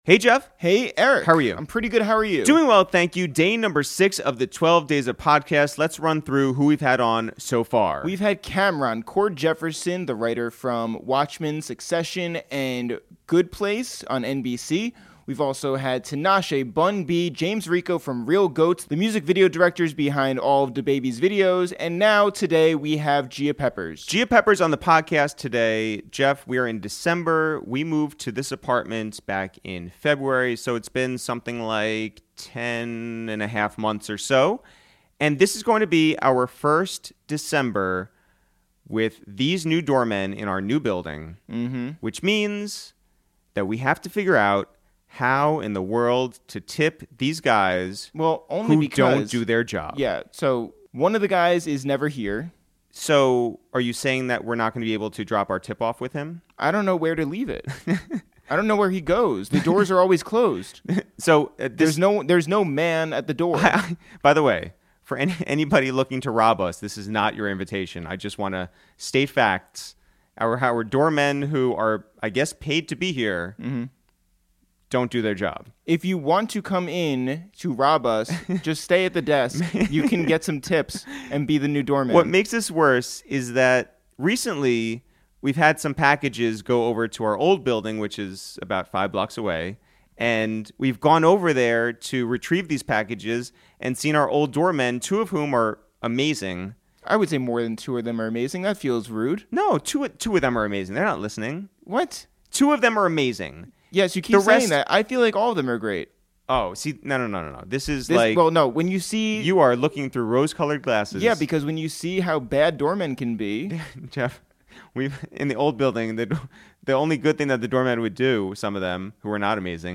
to the Upper West Side
a wide-ranging and personal conversation!